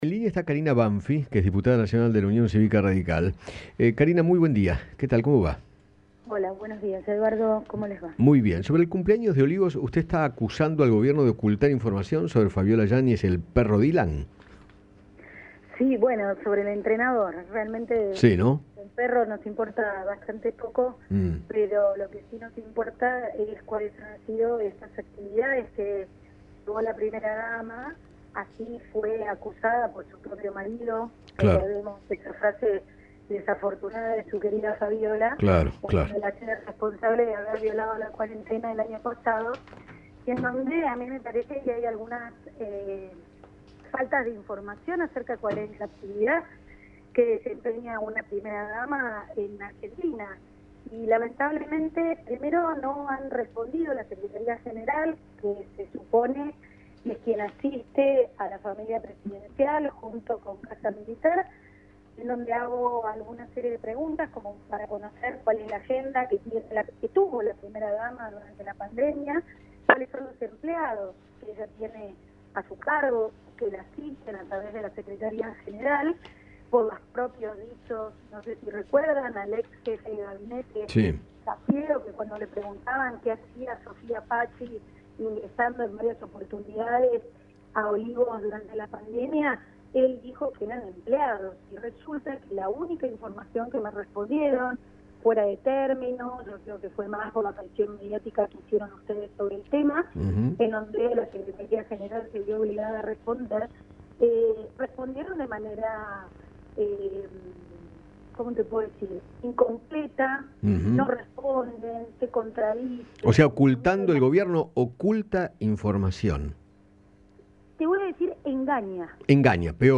La diputada Karina Banfi conversó con Eduardo Feinmann sobre el rol de la primera dama y  cuestionó los fondos con los que realiza sus actividades protocolares.